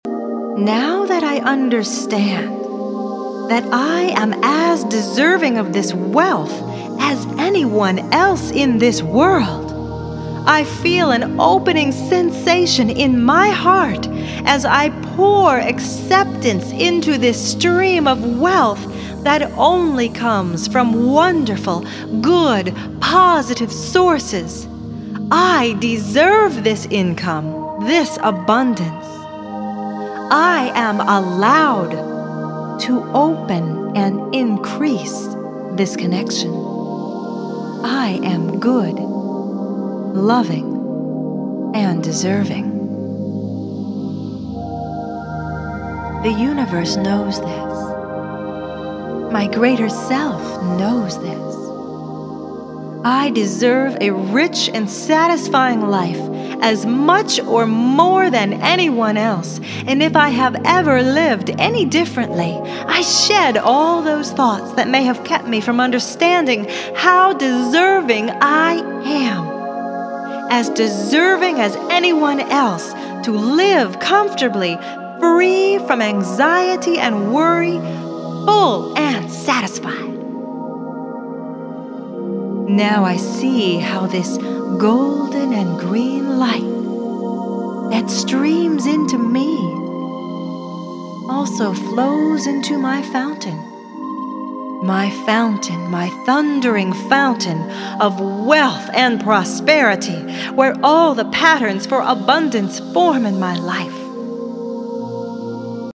Each audio session guides you through vivid, super-focused scenarios and metaphorical imagery within an intense daydream-like state.